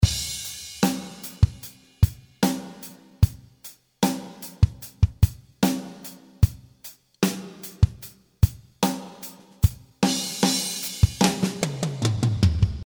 Heavy rock drum loops in 75 bpm.
15 loops of hard rock style drums.
All the loops are with heavy snare and ride cymbal all the way.
The first 4 loops are bass drum with close hihat and no snare , will be great using them in the first part of your track.
In the preview you can hear the free loops when using them with midi bass guitar and virtual rhythm guitar.